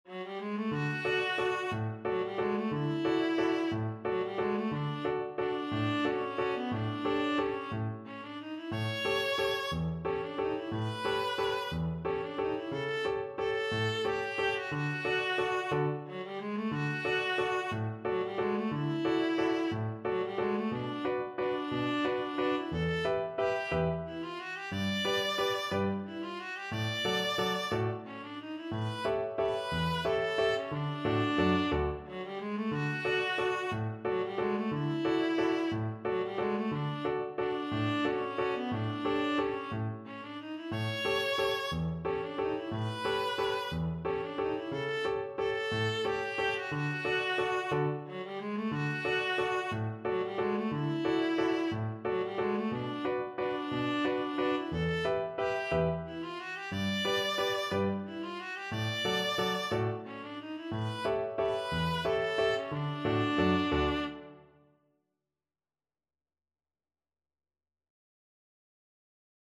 Viola version
3/4 (View more 3/4 Music)
One in a bar .=c.60